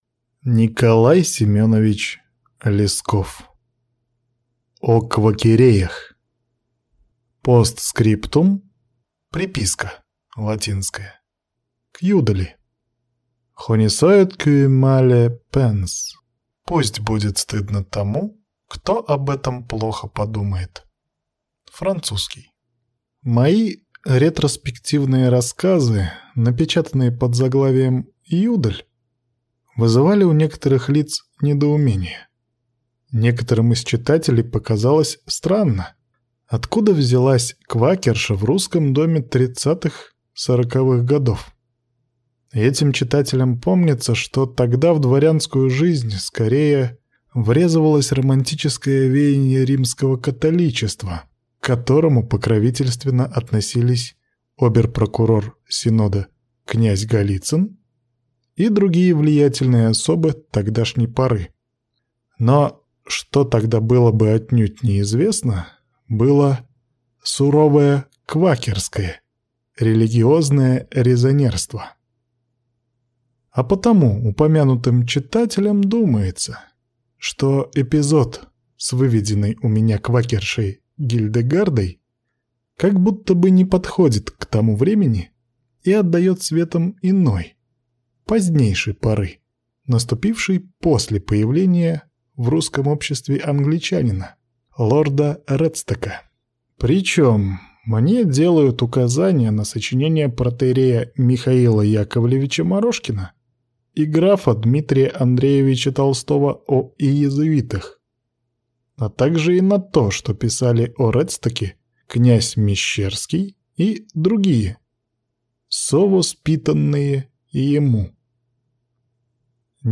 Аудиокнига О «Квакереях» | Библиотека аудиокниг